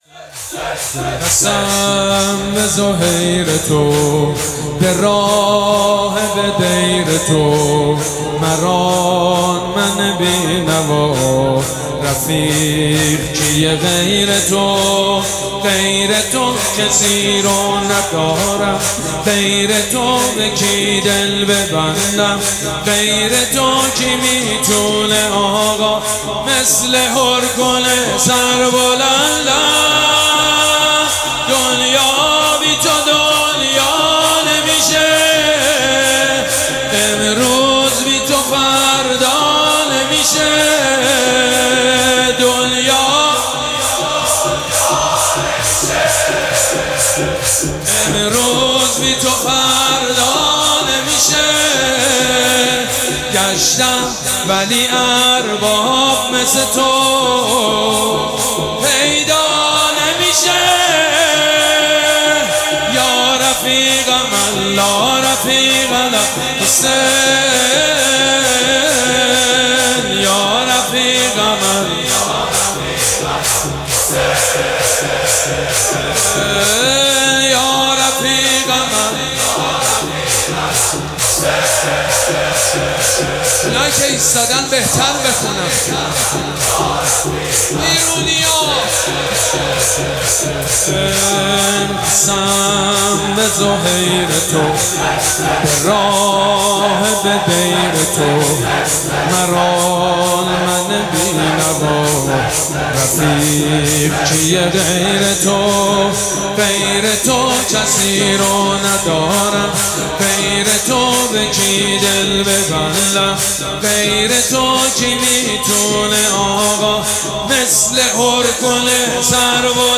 مراسم عزاداری شب دهم محرم الحرام ۱۴۴۷
شور
حاج سید مجید بنی فاطمه